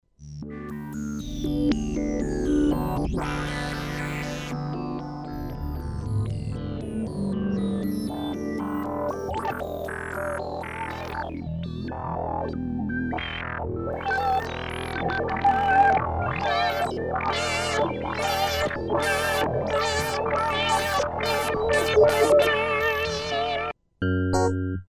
sample and hold
Class: Synthesizer